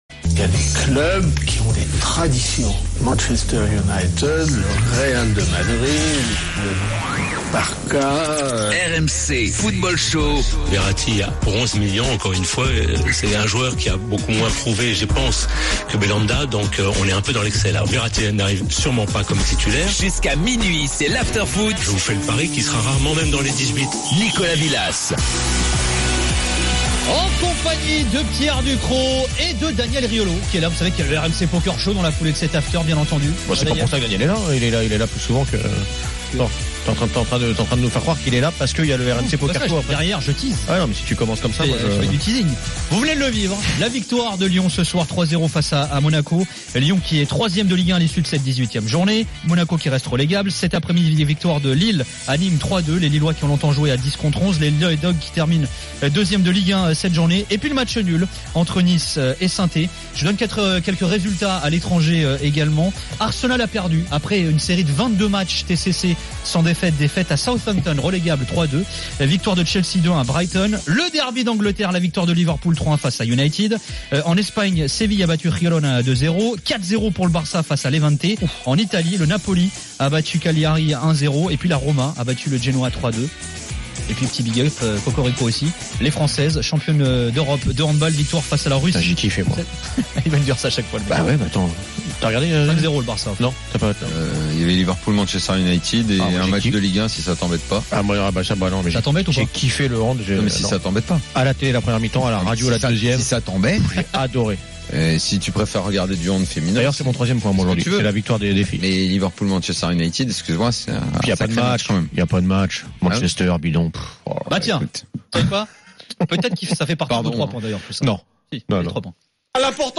le micro de RMC est à vous !